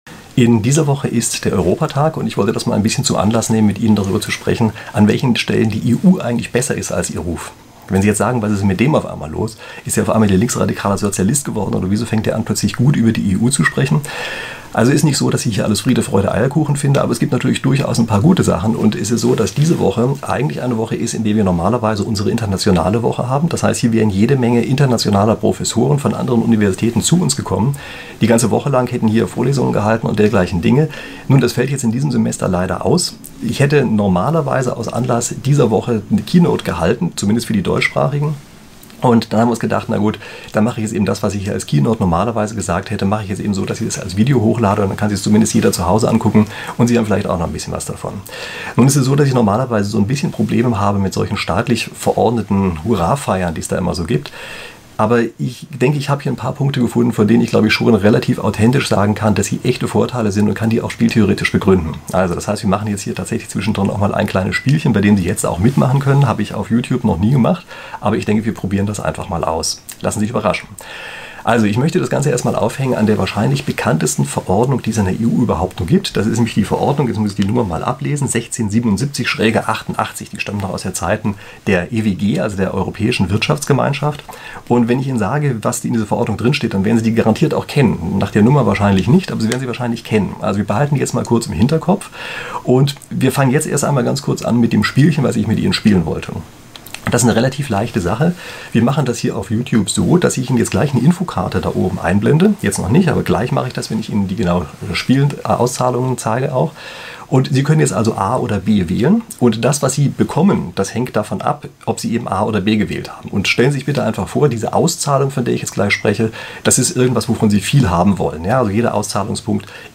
Keynote zur internationelen Woche an unserer Uni für die Gäste, die in diesem Jah